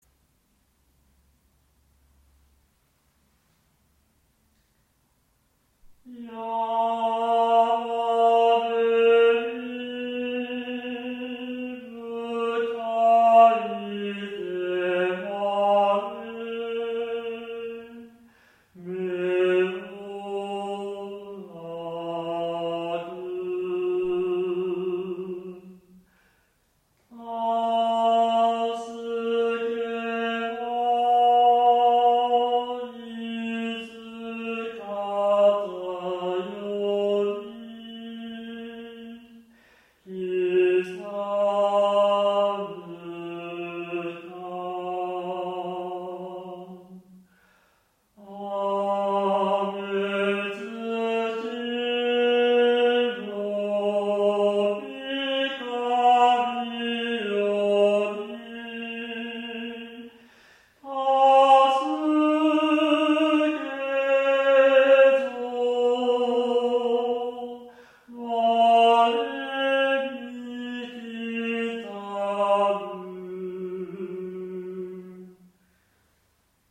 前半はミーントーンで甘めに、後半はピタゴラス的なかっちりした音程で
人の思いの内証性と神の救いの客体性のドラマを作ってみました。
言葉の繊細さとほのかなロマンティシズムを織り混ぜています。